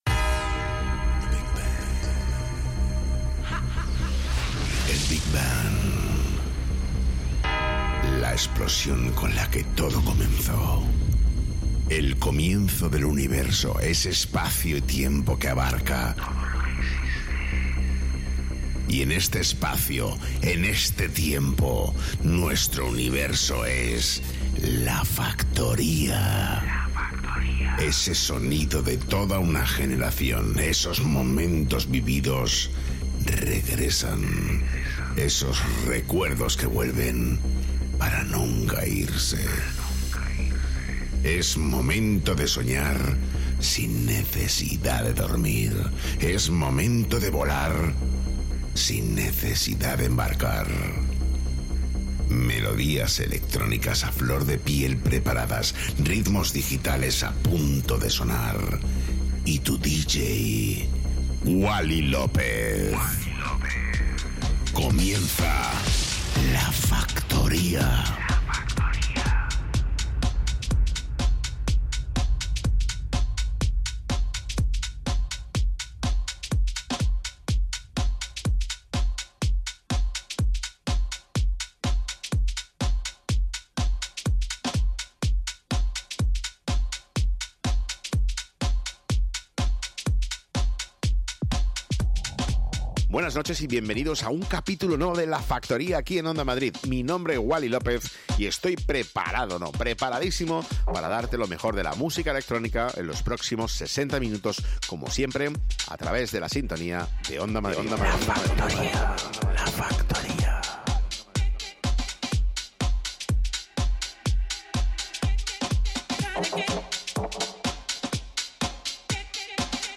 el DJ más internacional de Madrid